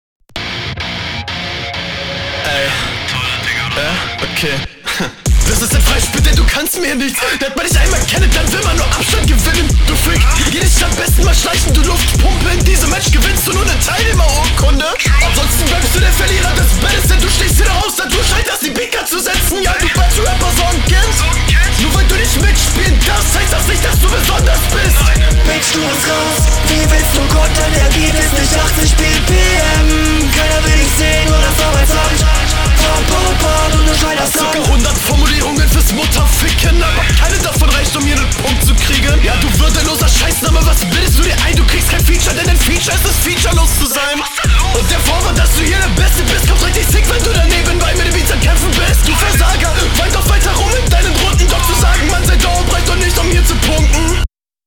Sehr geiler Sound.